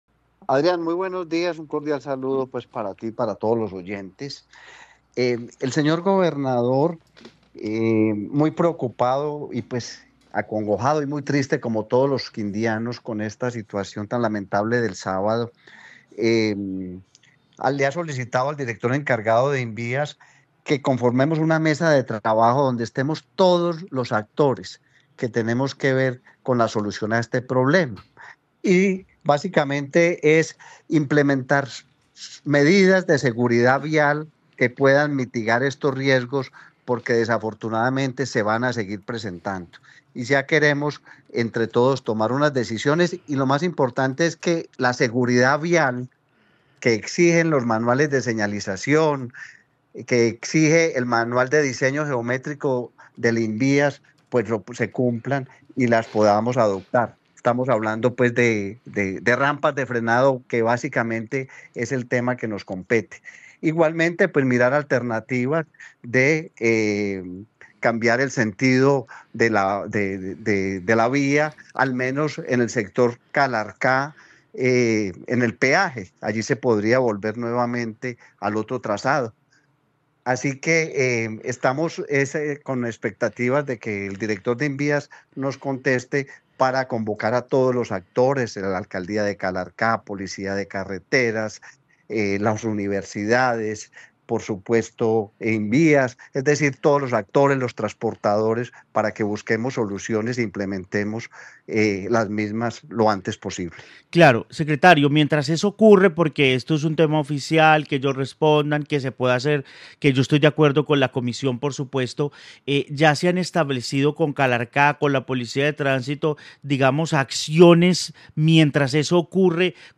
Luis Guillermo Agudelo, secretario de infraestructura del Quindío
En Caracol Radio Armenia hablamos con Juan Guillermo Agudelo, secretario de infraestructura del Quindío y el oficio que enviaron firmado además por el gobernador del Quindío al director del Invías solicitando acciones frente a la condición del puente Helicoidal en Calarcá, Quindío.